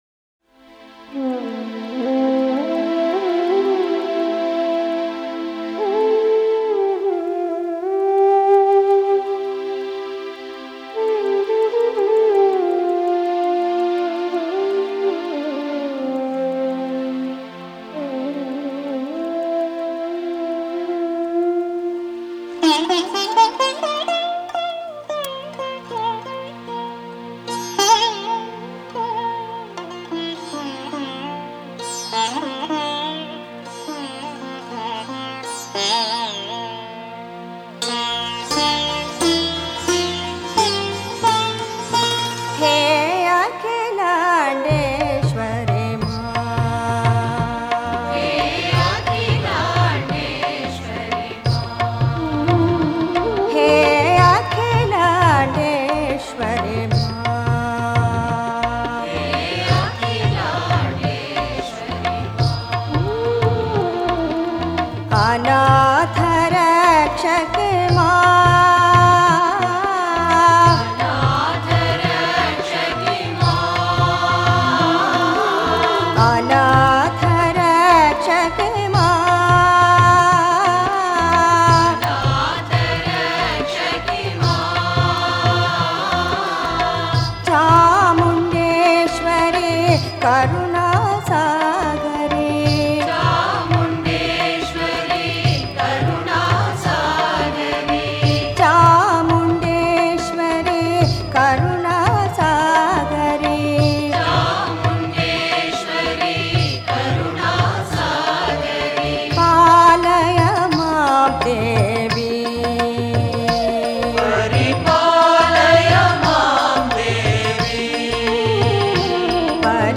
Home | Bhajan | Bhajans on various Deities | Devi Bhajans | 15 – HEY AKHILANDESHWARI MAA